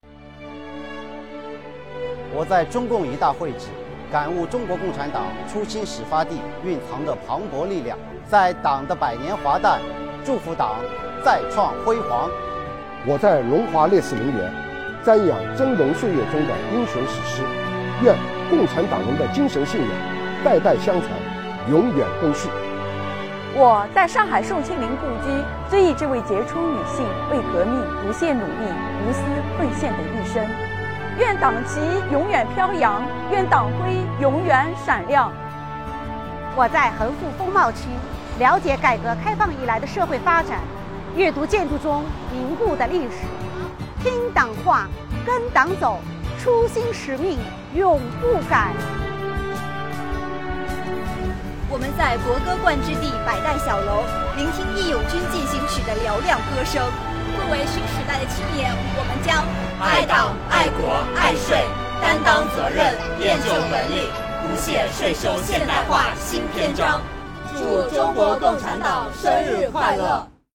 上海市徐汇区税务局深入推进党史学习教育，依托上海红色文化底蕴开展红色寻访活动，组织党员干部走红路、忆初心、表祝福，在中共一大会址、龙华烈士陵园、衡复风貌区、上海宋庆龄故居、国歌灌制地百代小楼等红色寻访地立下税务干部铿锵有力的誓言，传达拳拳爱党之心。